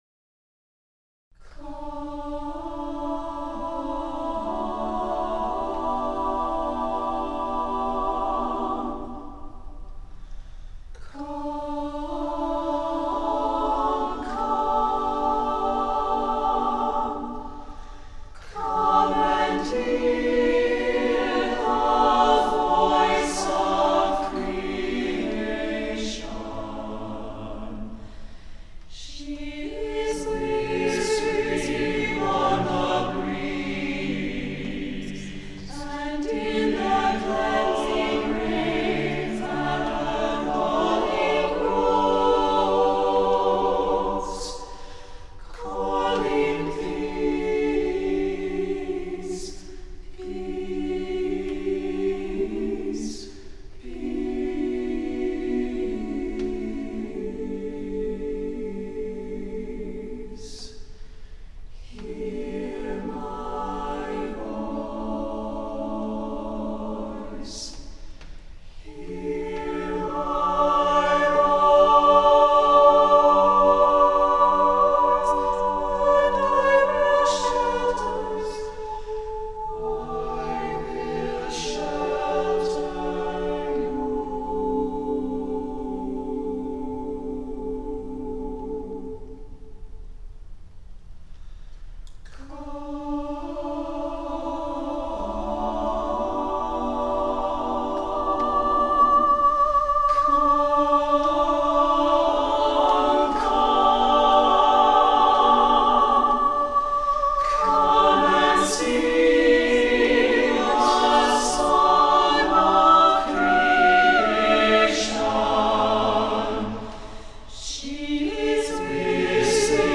Voicing: SATB a cappella and Soprano Solo